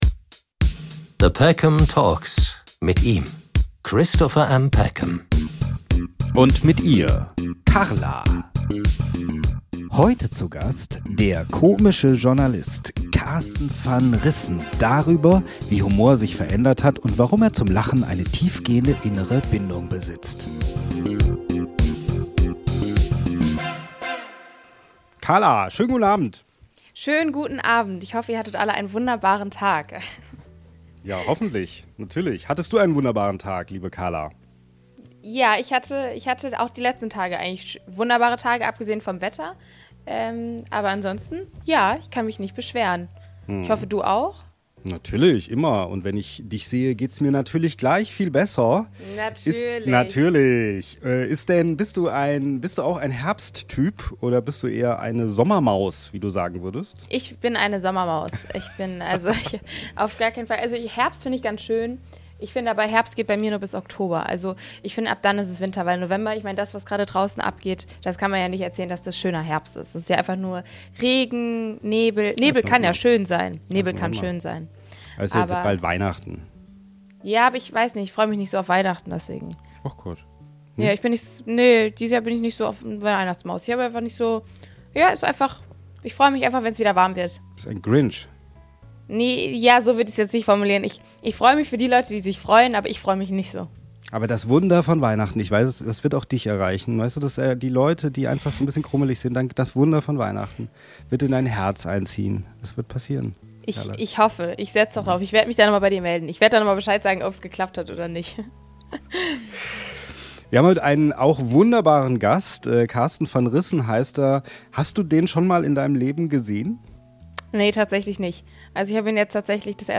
Talkshow